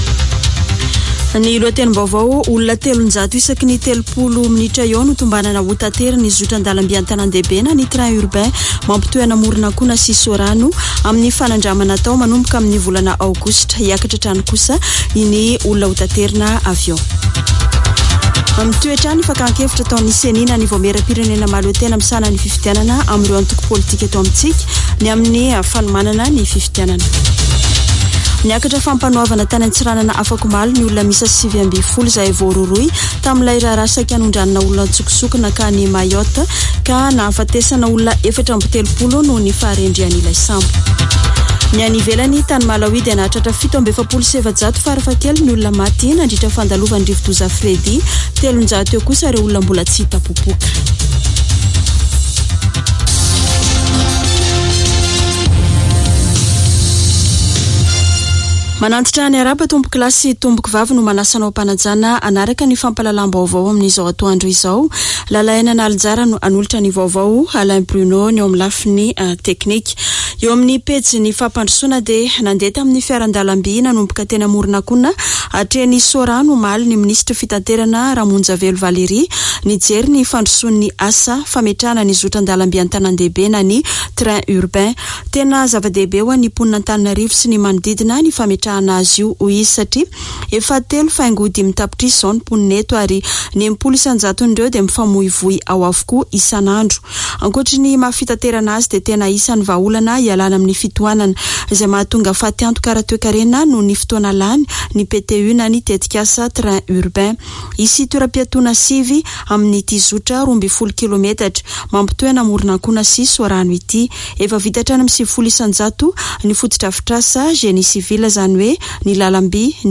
[Vaovao antoandro] Alarobia 22 marsa 2023